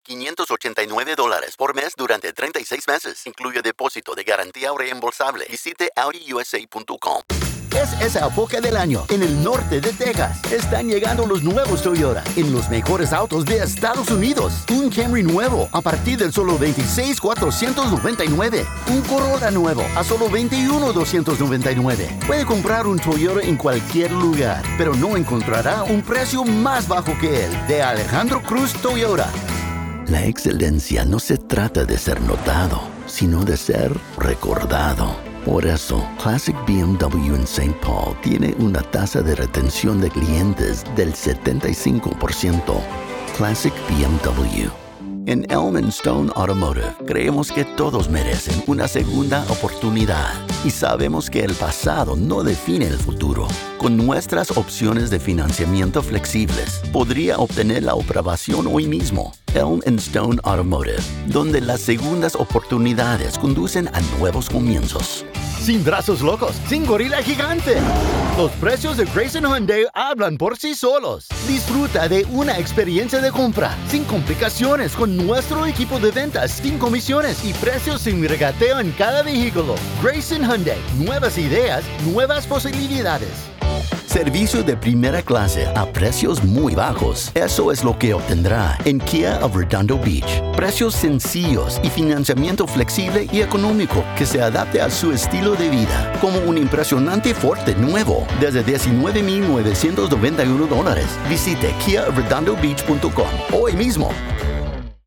Full-Time, award-winning, bilingual voice actor with a pro studio.
Automotive Demo - Spanish
Southern, Hispanic, Mexican, Latino, American Standard English
Middle Aged